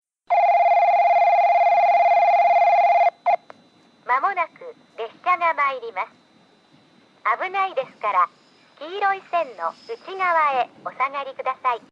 1番のりば接近放送　女声 かつての放送は、九州カンノ型Bでした。実際の放送は2回流れます。
スピーカーはTOAラッパ型でした。